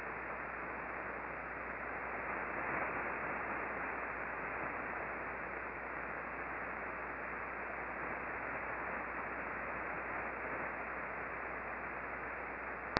We used two Icom R-75 HF Receivers, one tuned to 19.432 MHz (LSB), corresponding to the Red trace in the charts below, and the other tuned to 20.342 MHz (LSB), corresponding to the Green trace.
The antenna was an 8-element log periodic antenna pointed at a fixed azimuth of 79 degrees true (no tracking was used).
We observed mostly S-bursts but some sounded like fast L-bursts.
Click here for a stereo audio file corresponding to the time period 0732:57 to 0733:10